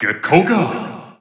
The announcer saying Greninja's name in Japanese and Chinese releases of Super Smash Bros. 4 and Super Smash Bros. Ultimate.
Greninja_Japanese_Announcer_SSB4-SSBU.wav